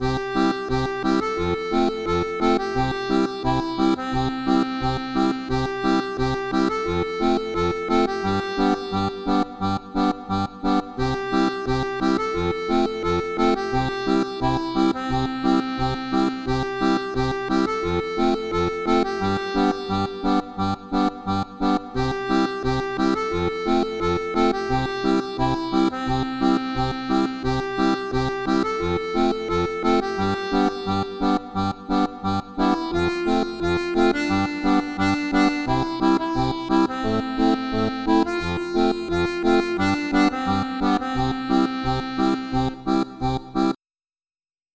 Folk